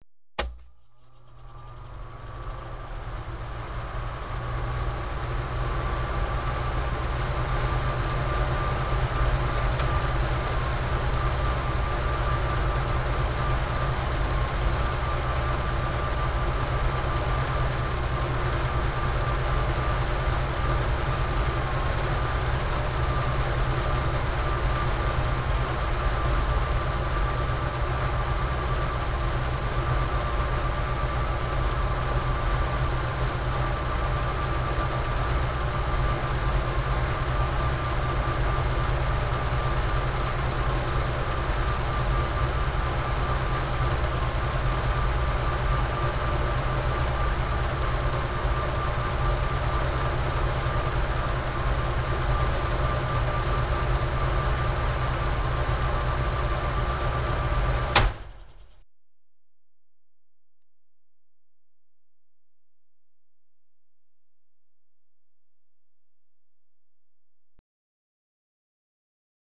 household
Humidifier Noise